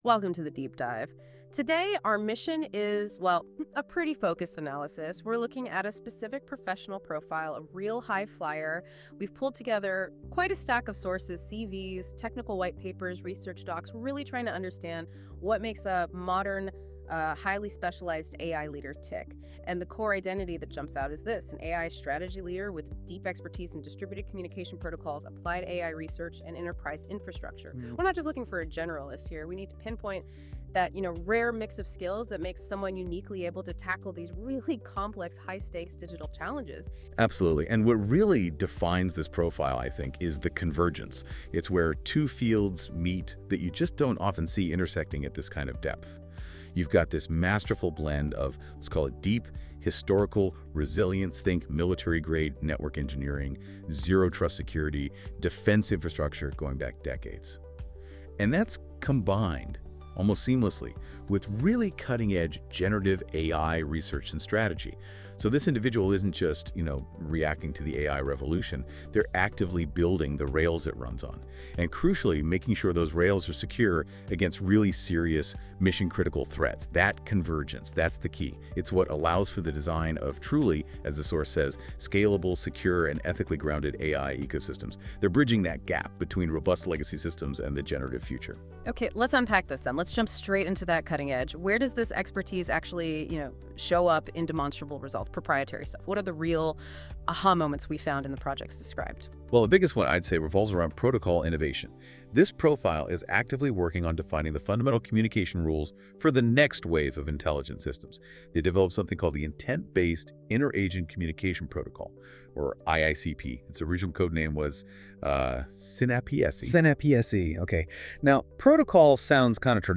Two analysts. 13 minutes. No marketing speak—just work history reviewed by people who know what they're looking at.